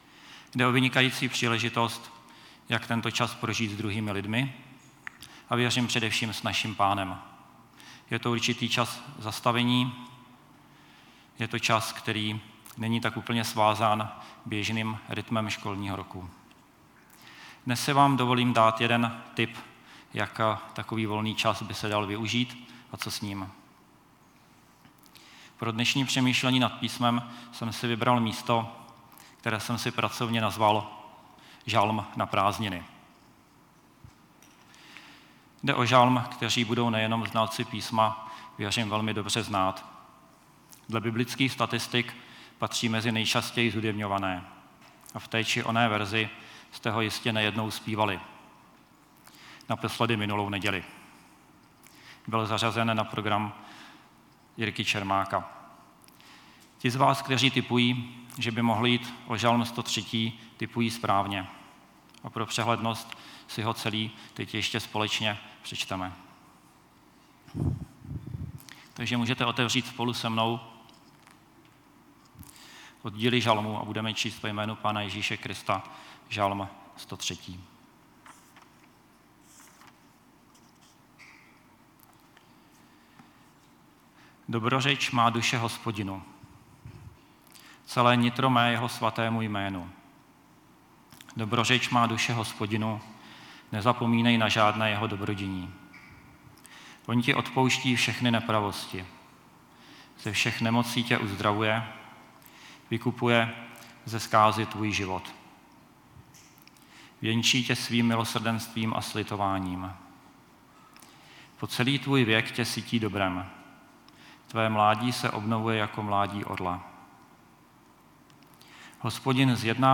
Nedělní bohoslužba